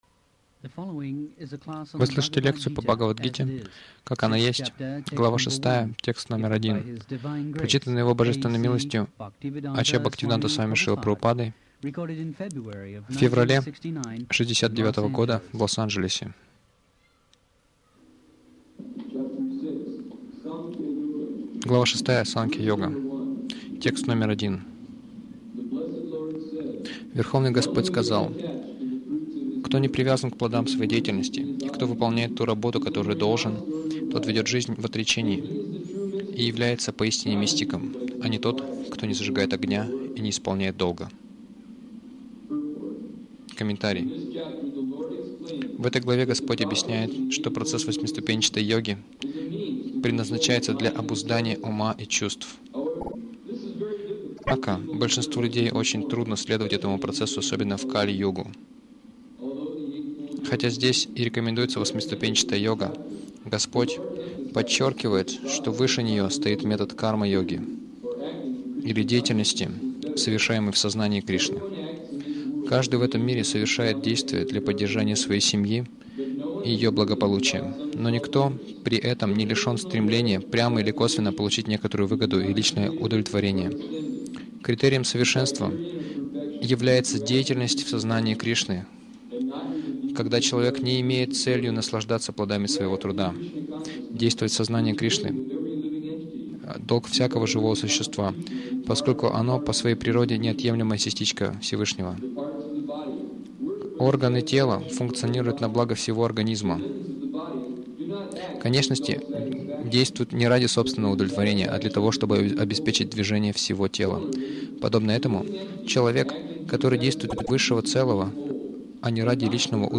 Милость Прабхупады Аудиолекции и книги 10.02.1969 Бхагавад Гита | Лос-Анджелес БГ 06.01 — Всё духовно Загрузка...